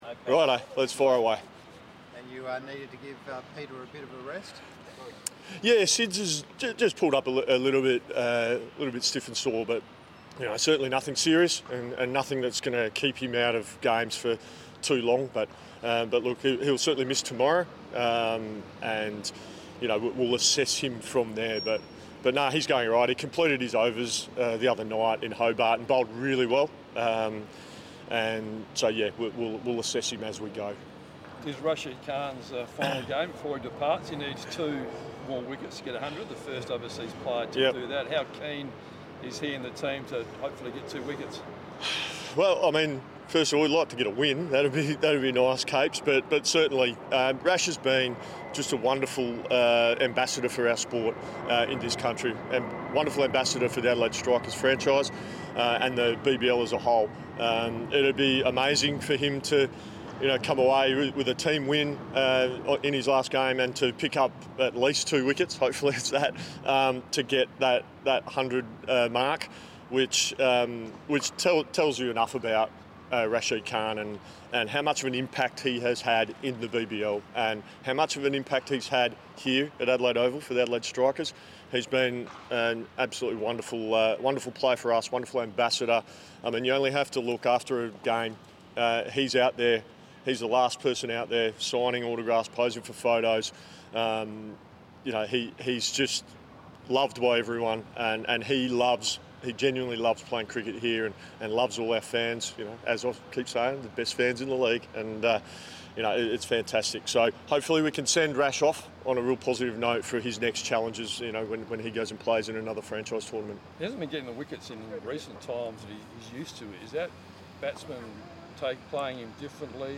Adelaide Strikers Head Coach Jason Gillespie speaks about the teams performance and Rashid Khan